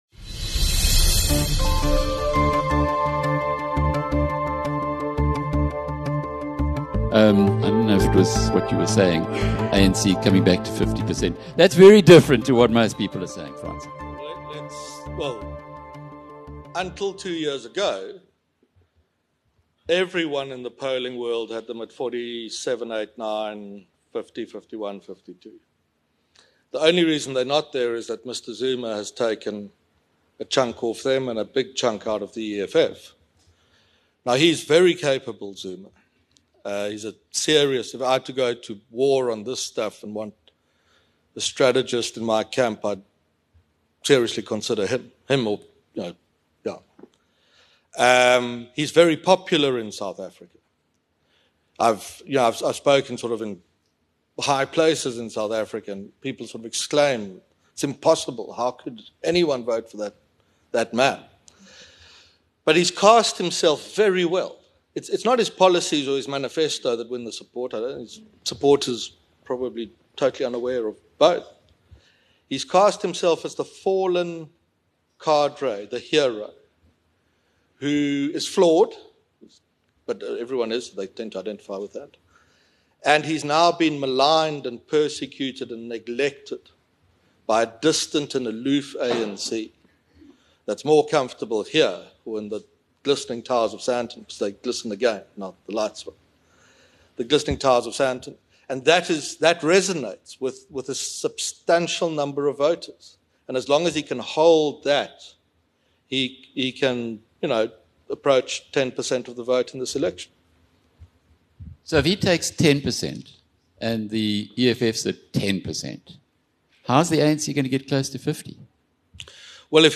Q&A
At the BizNews Conference in London